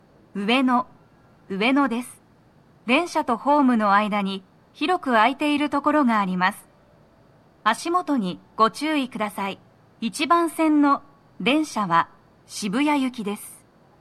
足元注意喚起放送が付帯されており、多少の粘りが必要です。
1番線 渋谷方面 到着放送 【女声
到着放送1